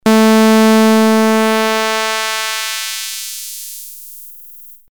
試しにOSCをノコギリ波にしてFrequencyツマミを回してみよう。
ペラペラな「ミーン」という音になる。